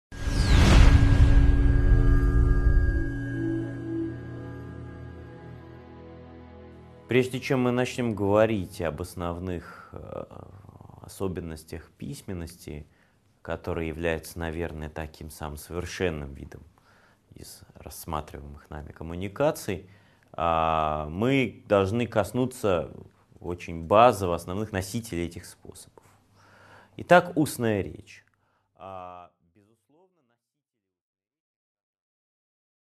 Аудиокнига 4.3 Носители способов коммуникации | Библиотека аудиокниг